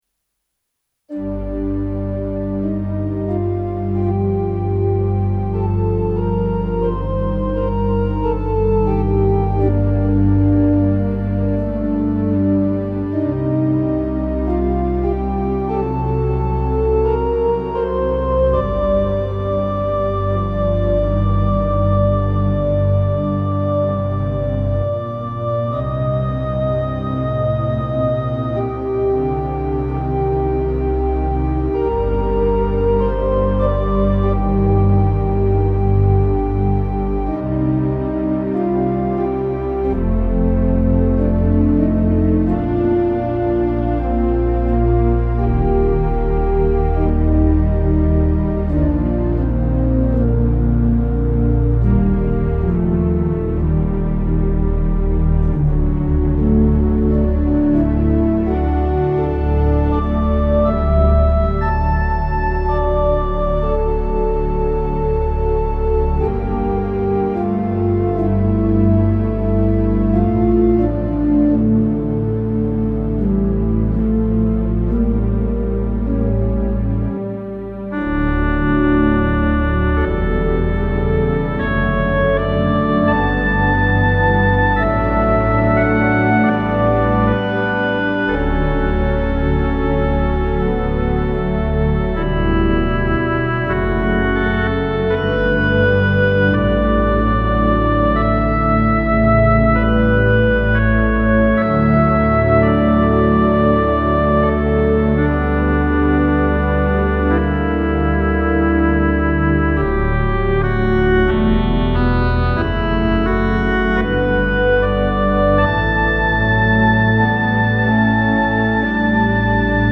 St. James Memorial Episcopal Church
1909 Austin Organ, Opus 246